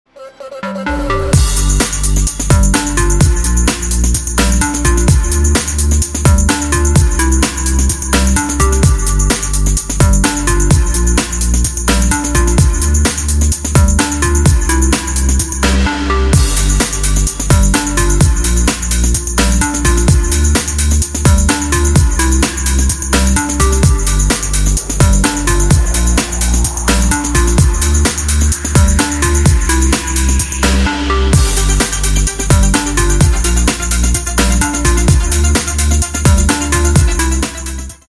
Клубные Рингтоны » # Рингтоны Без Слов
Танцевальные Рингтоны